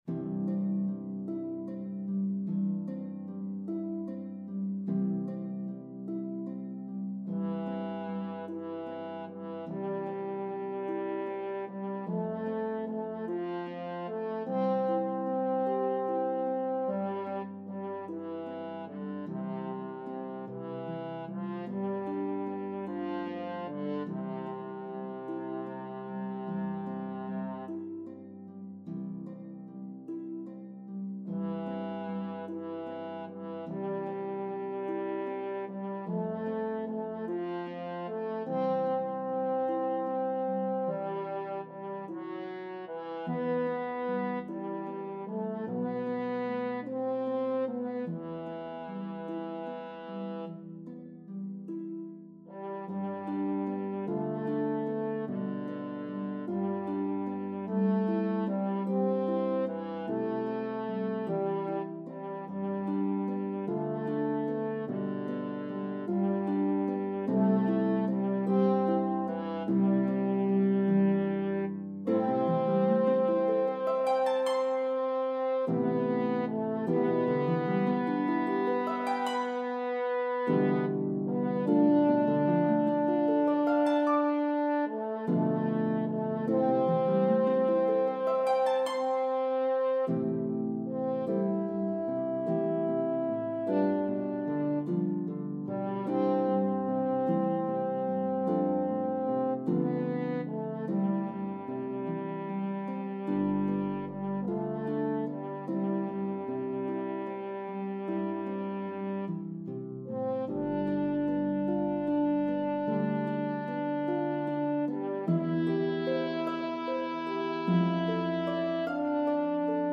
34+ string Lever Harp
The melody of the two verses varies in rhythm & pitches.